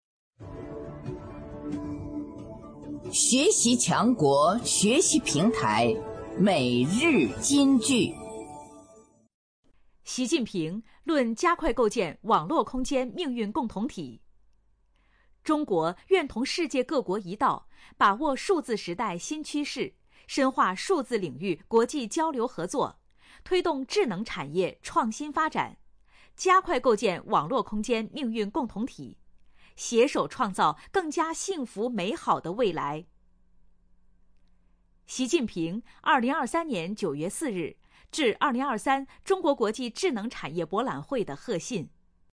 每日金句（朗读版）|习近平论加快构建网络空间命运共同体 _ 学习宣传 _ 福建省民政厅